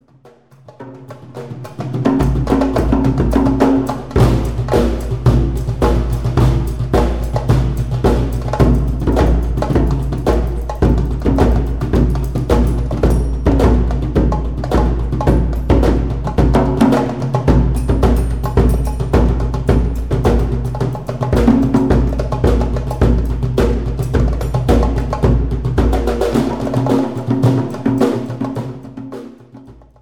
Percussion Solo 2